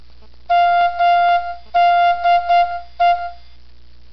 En esta, el nombre “Medellín” fue traducido a clave Morse (sistema de señales intermitentes que se usaba sobre todo para los telegramas y la radio) y luego transmitido por los 1.690 kilociclos del a.m., frecuencia de radio local específicamente asignada para el acontecimiento.
Señal: MDE, acción sonora, ondas sonoras transmitidas en la radio, 7 s., 1981.